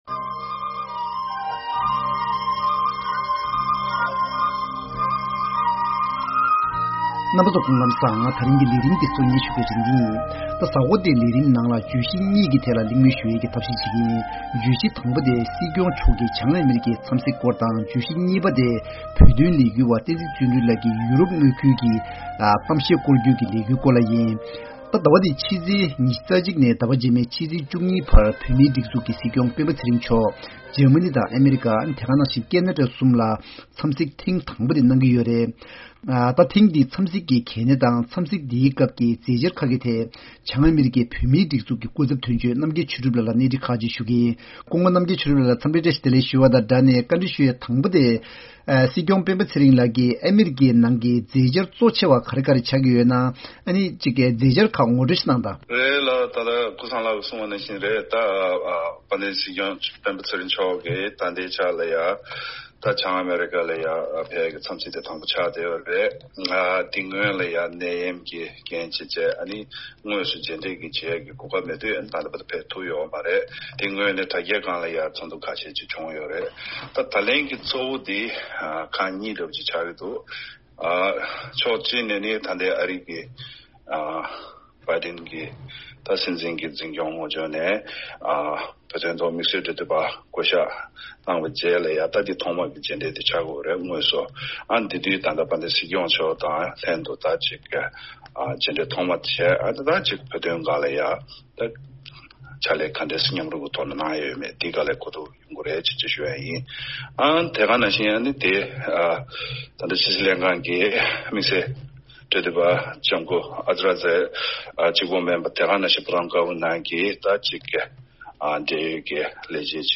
༄༅། བོད་མིའི་སྒྲིག་འཛུགས་ཀྱི་སྲིད་སྐྱོང་སྤེན་པ་ཚེ་རིང་མཆོག་གི་ཨ་རིའི་ནང་གཞུང་འབྲེལ་འཚམས་གཟིགས་ཐེངས་དང་པོ་དེའི་གལ་གནད་དང། དེ་བཞིན། བོད་དོན་ལས་འགུལ་བ་བསྟན་འཛིན་བརྩོན་གྲུས་ལགས་ཀྱིས་ཡོ་རོབ་མངའ་ཁུལ་ལ་བོད་དོན་དྲིལ་བསྒྲགས་ཀྱི་བསྐོར་བསྐྱོད་ལས་འགུལ་སྤེལ་བཞིན་པ་གཉིས་ཀྱི་སྐོར་གླེང་མོལ་ཞུས་པ་གསན་རོགས།